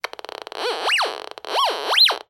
На этой странице собраны звуки металлодетекторов — от стандартных сигналов до вариаций при обнаружении разных металлов.
Звуки арочного металлодетектора: добавим для разнообразия